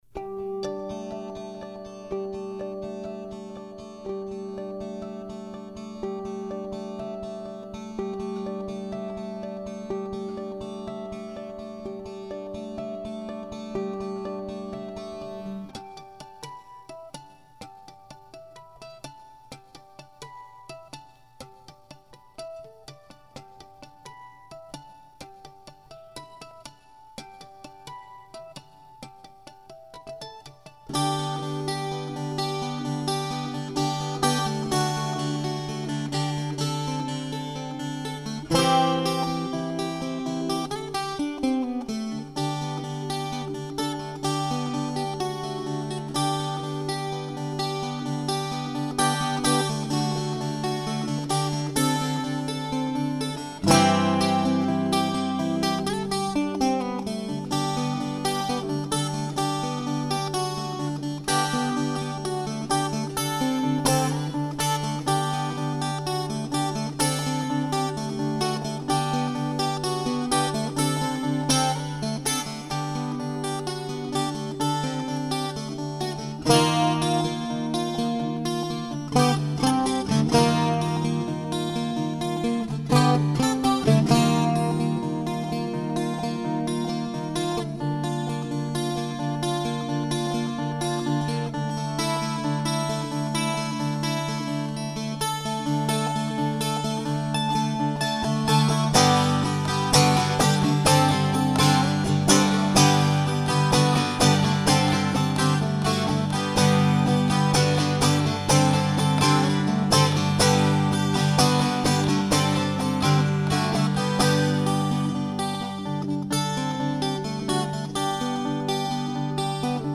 Juliet (solo bouzouki tune - 2012).